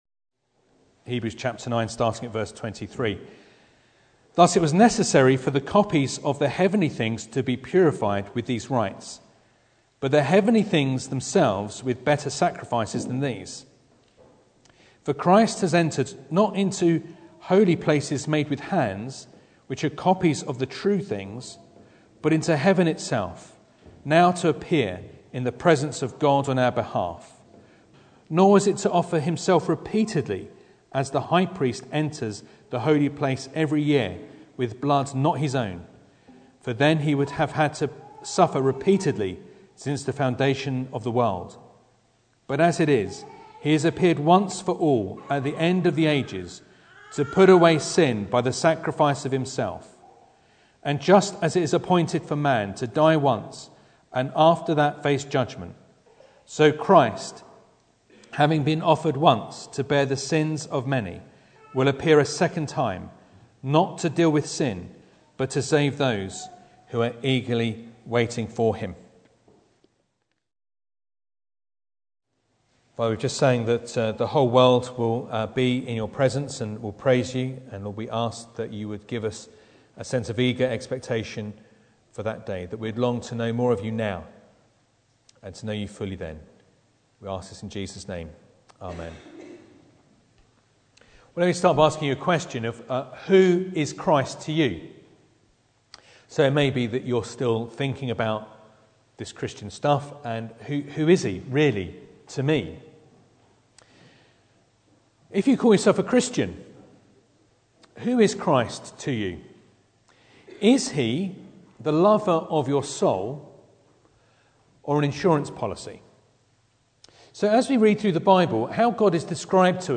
Hebrews 9:27-28 Service Type: Sunday Morning Bible Text